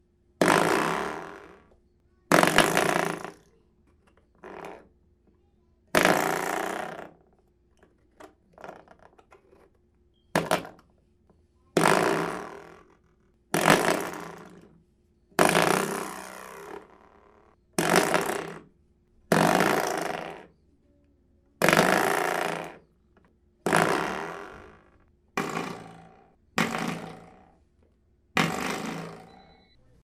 Category 🤣 Funny
boing coil comical door doorstop funny silly spring sound effect free sound royalty free Funny